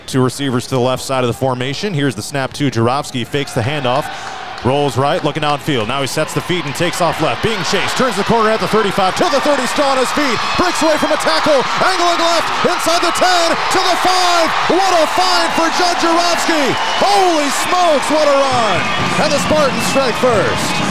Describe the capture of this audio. Perfection times three. That’s what the #1 (1A) Grundy Center football team achieved with Thursday’s 28-7 win over #2 (1A) Dike-New Hartford in the Class 1A Championship game, as aired on 99 The Wave.